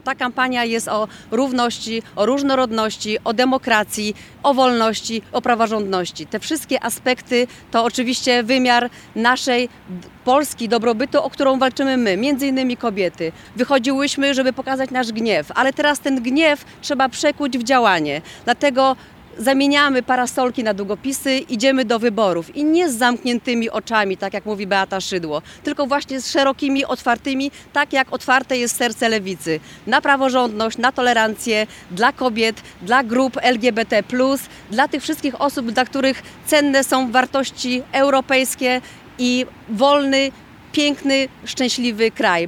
Kandydaci na posłów i senatorów z Nowej Lewicy zwołali konferencję prasową podsumowującą kampanię.